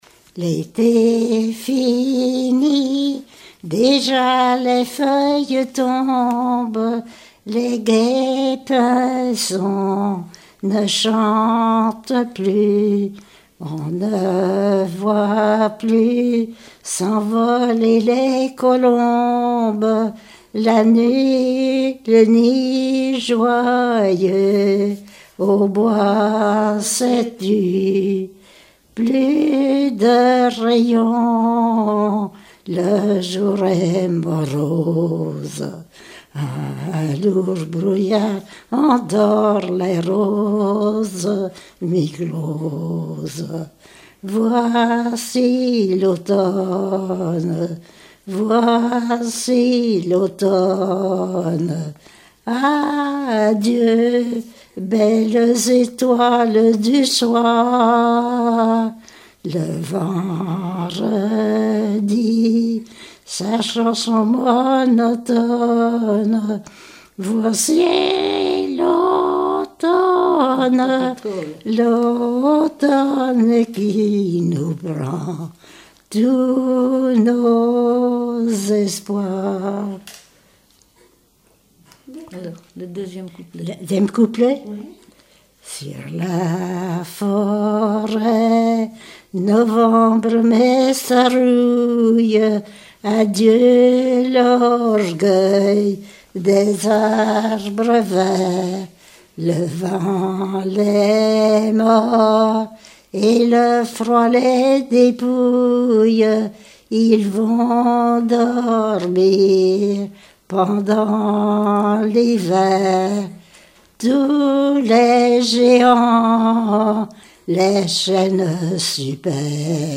Mémoires et Patrimoines vivants - RaddO est une base de données d'archives iconographiques et sonores.
Genre strophique
chansons d'écoles et populaires
Pièce musicale inédite